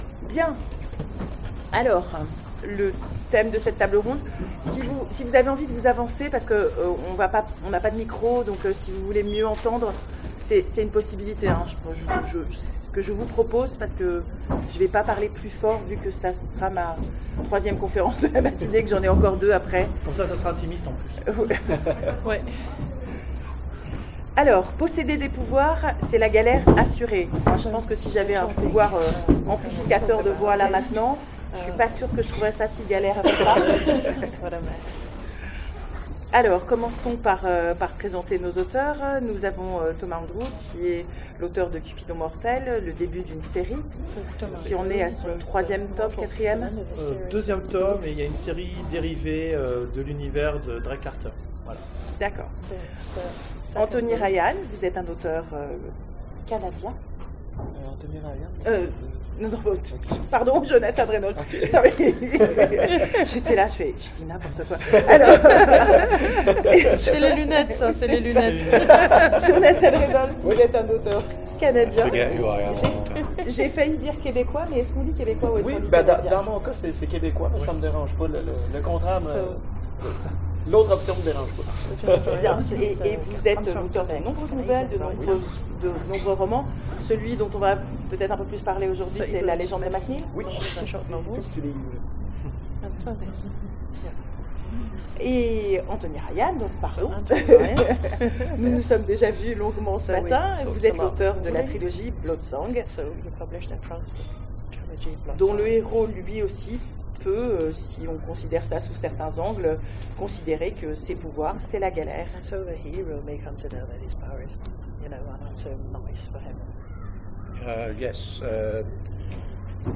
Imaginales 2016 : Conférence Posséder des pouvoirs ?
Imaginales_2016_conference_posseder_des_pouvoirs_ok.mp3